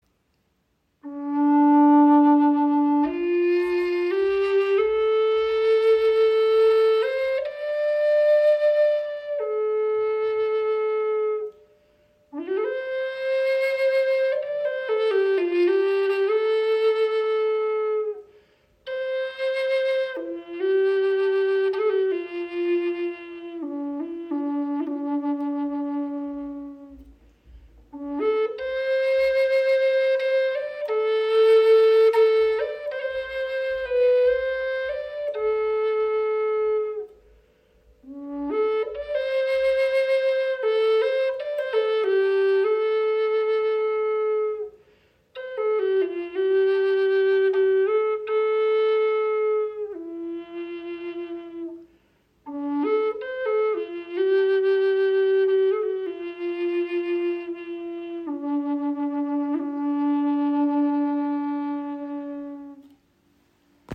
Gebetsflöte aus Curly Walnut in D-Moll | Humpback Whale • Raven Spirit
• Icon Gesamtlänge 69  cm, 25  mm Innenbohrung – warmer, klarer Klang
Das dichte, resonante Holz erzeugt einen runden, vollen Ton mit klarer Mitte und weichen Obertönen.
Wie diese uralten Klangrufe entfaltet auch die Flöte eine ruhige, sanft windige Stimme – tief, berührend und zugleich weich schwingend.
Die Stimmung D schenkt der Flöte eine volle, erdige Klangfarbe.